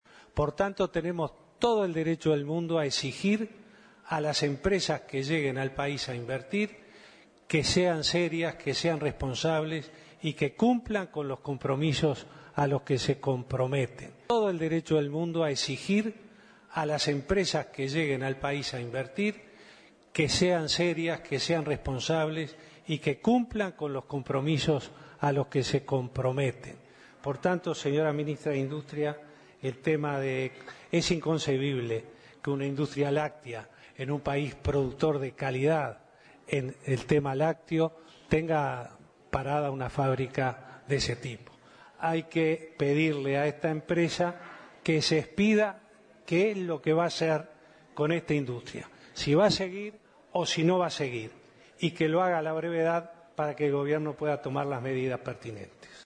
Se realizo el tercer Consejo de Ministros público en Dolores, Soriano.